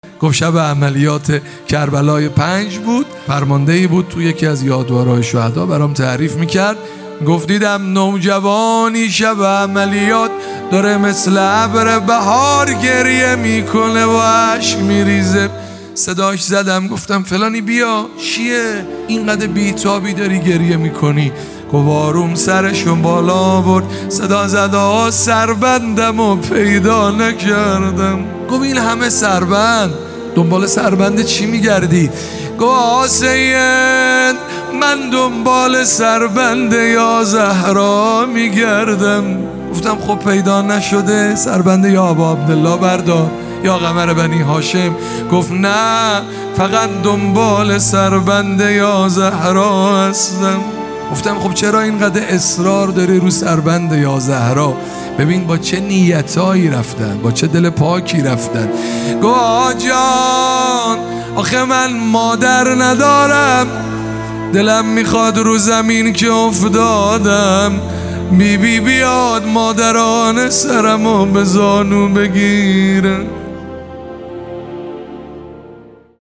روضه سربند یا زهرا
یادواره شهدای گمنام کیش